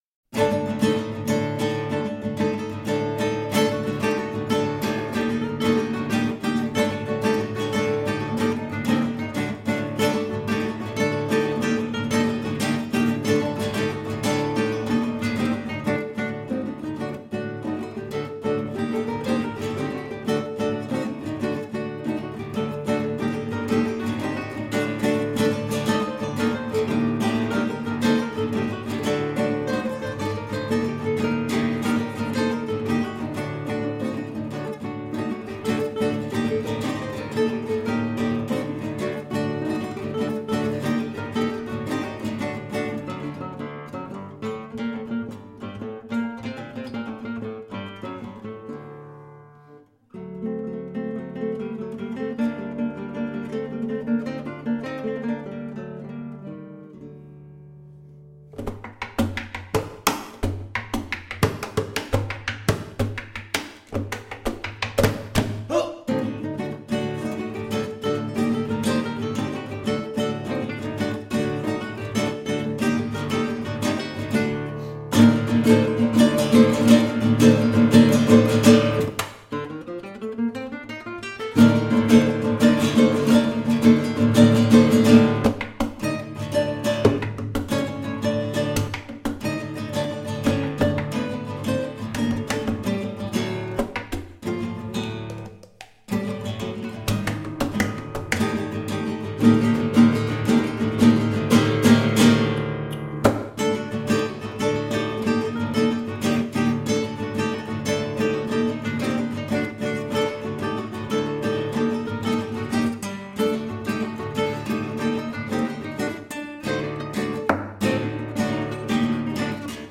2007 Fondation de Guitartare (duo de guitare classique)